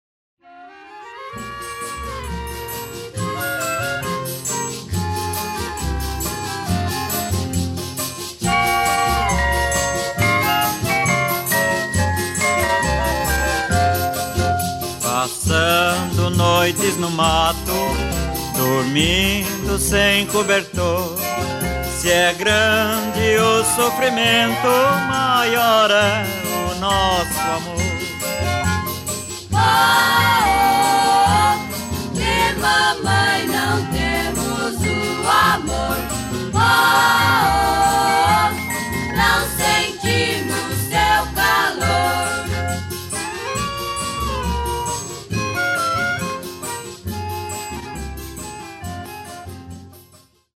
toada 1960